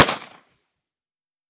38 SPL Revolver - Gunshot B 002.wav